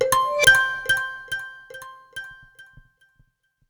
notification_009.ogg